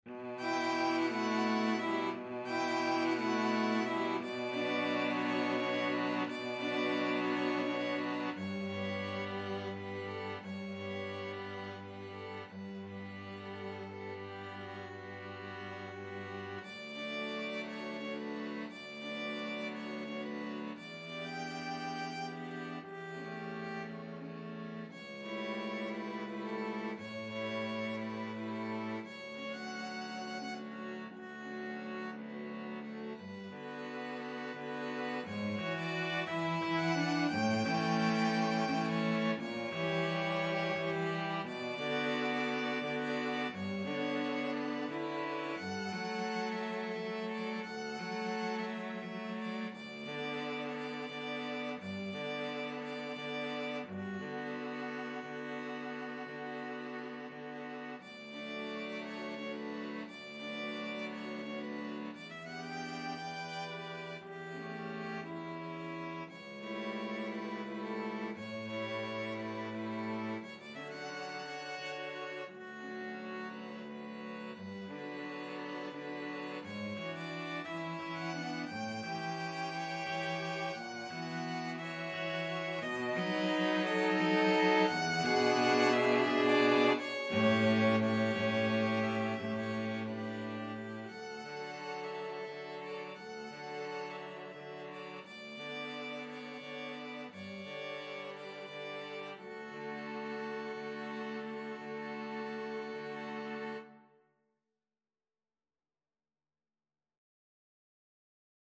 Antonín Dvořák - Songs My Mother Taught Me (Als die alte Mutter) from Gypsy songs Free Sheet music for String Quartet
songs_my_mother_taught_STRQ.mp3